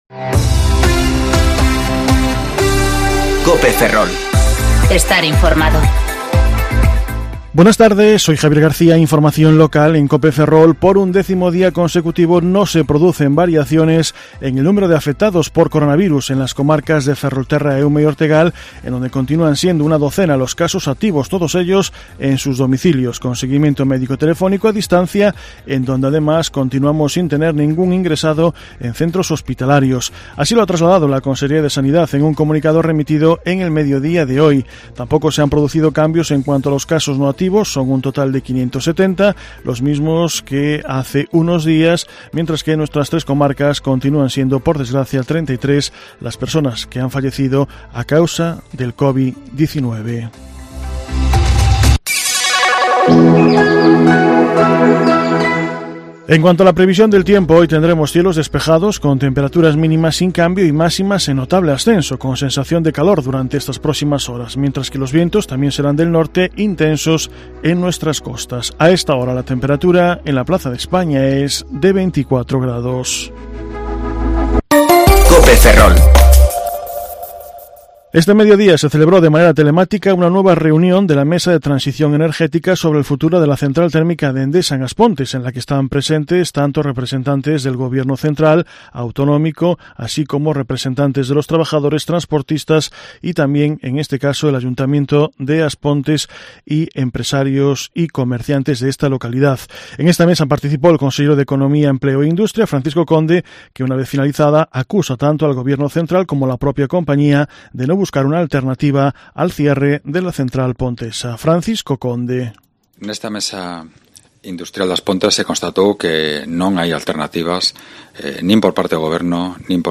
Informativo Mediodía COPE Ferrol 22/6/2020 (De 14,20 a 14,30 horas)